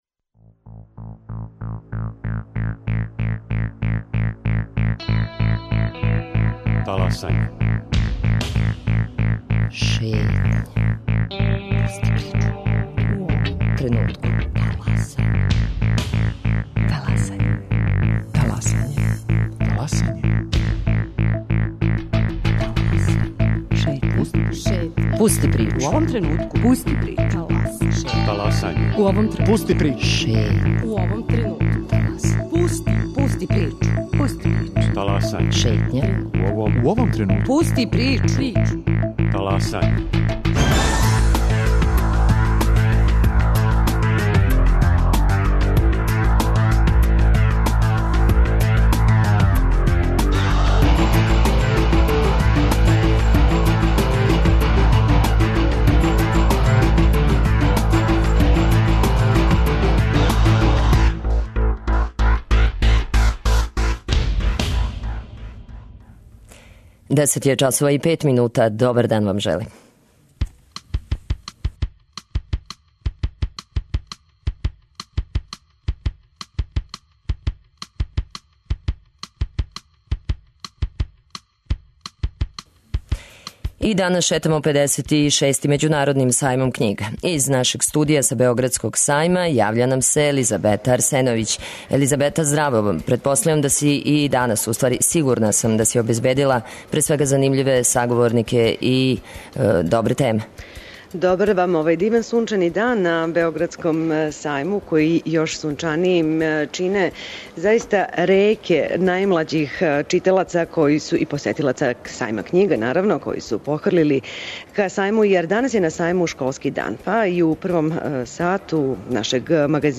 И данас шетамо 56. Међународним Сајмом књига у Београду – разговараћемо са издавачима, писцима, посетиоцима... У студију Београда 1 угостићемо познатог музичара Слободана Тркуљу.